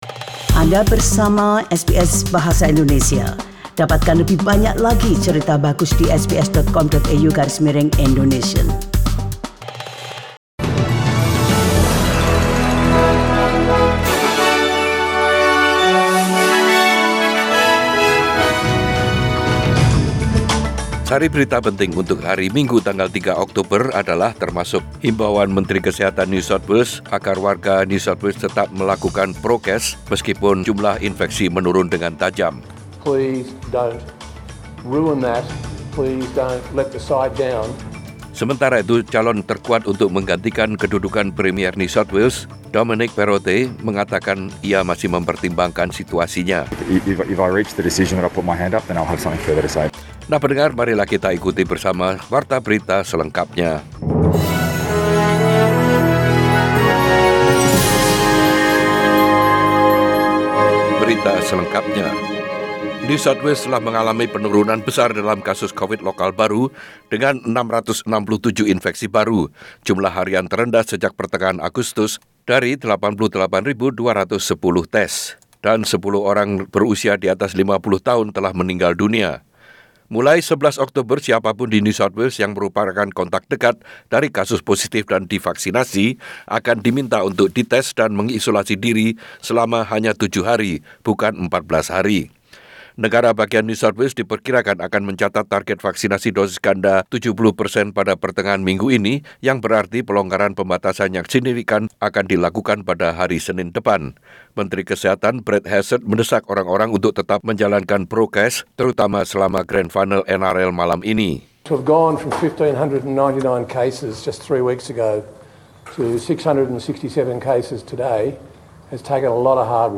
SBS Radio News in Bahasa Indonesia - 3 October 2021
Warta Berita Radio SBS dalam Bahasa Indonesia Source: SBS